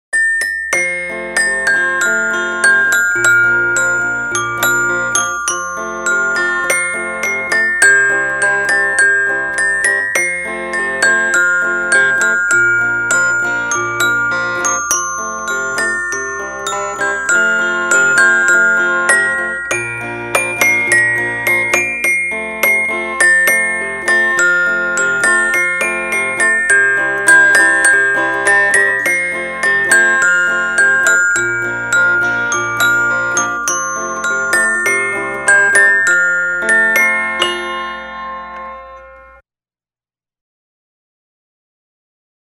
Marx guitarchimes
The Marx guitarchimes derives its melody from a built-in miniature xylophone.